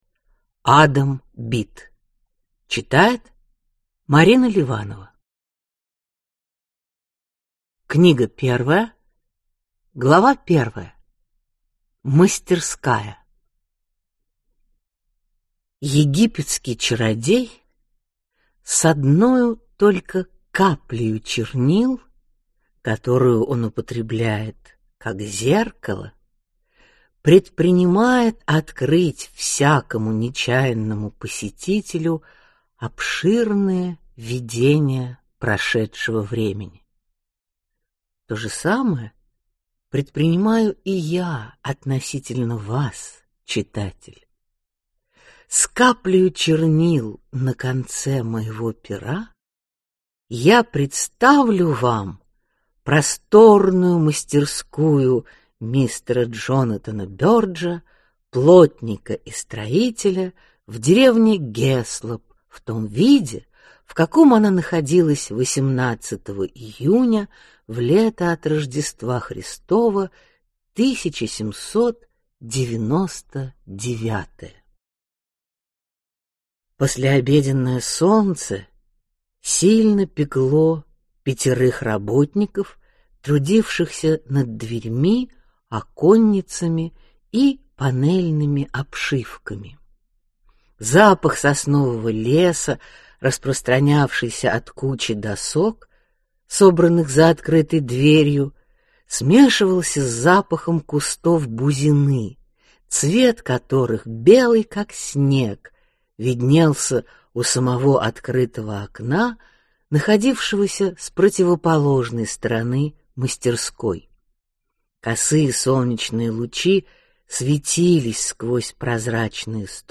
Аудиокнига Адам Бид. Часть 1 | Библиотека аудиокниг